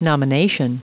Prononciation du mot nomination en anglais (fichier audio)
Prononciation du mot : nomination